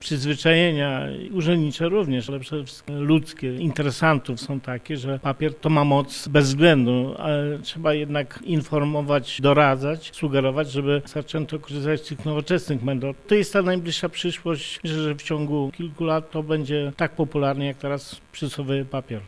Jednak zdaniem wójta Wojciechowa Jana Czyżewskiego, by korzystać w pełni z nowych możliwości trzeba jeszcze zmienić ludzką mentalność: